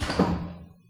switch_5.wav